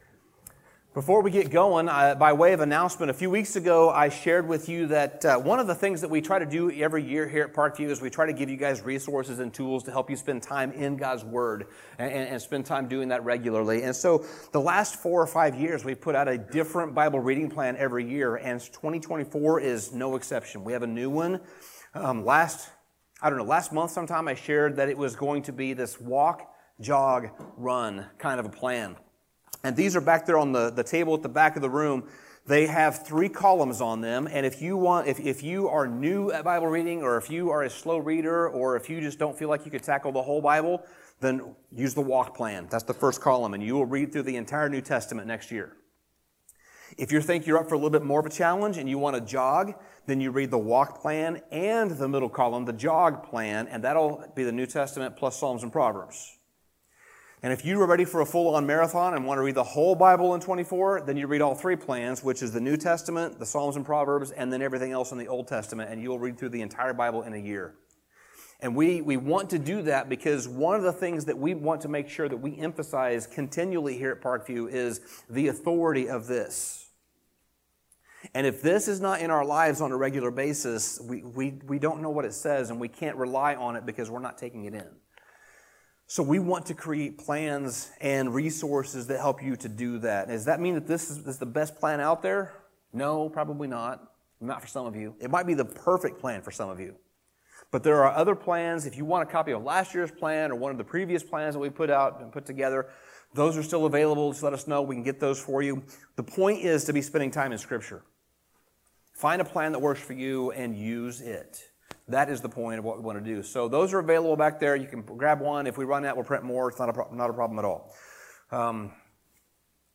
Sermon Summary Christmas is often a time when we feel the loneliest.